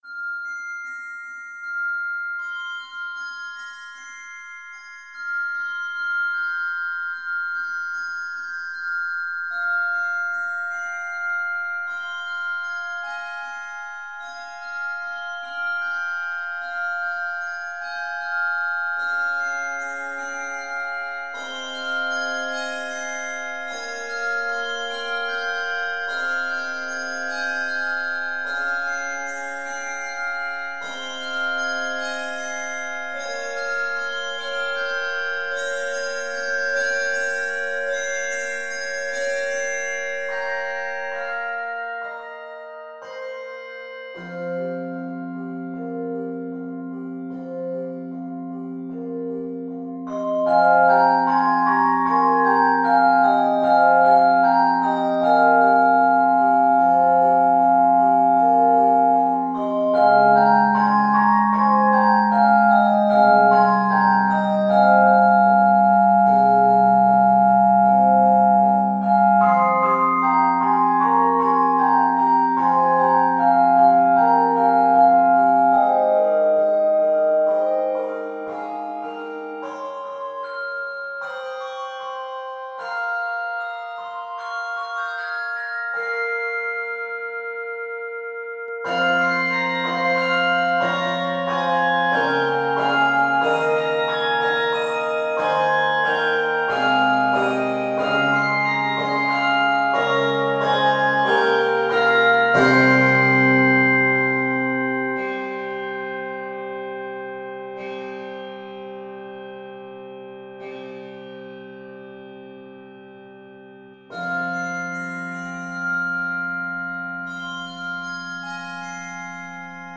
Canadian Christmas song